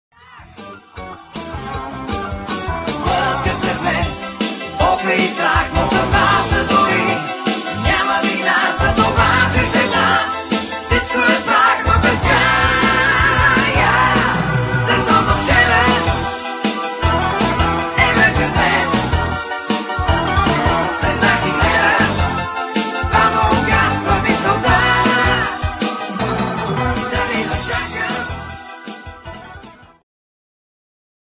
ジャンル HardRock
Progressive
シンフォニック系
シンフォニック・プログレとメロディック・ハード/AORの融合。